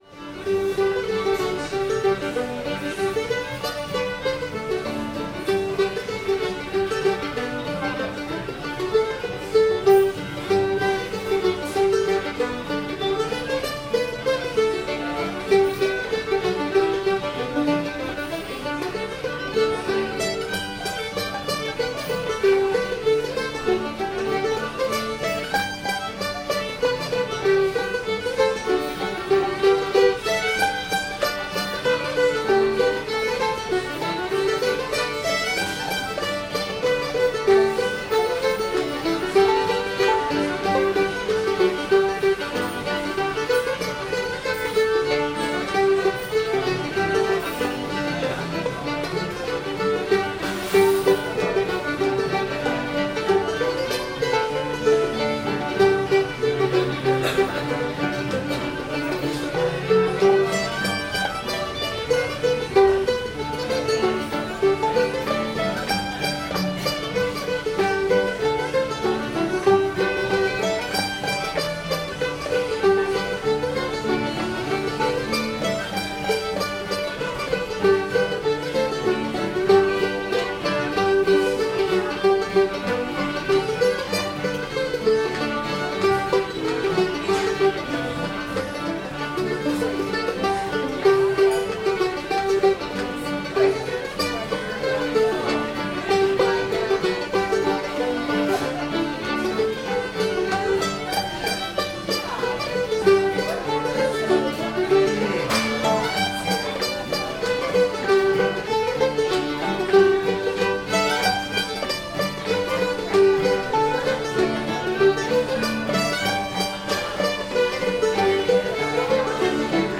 cowhide boots [G]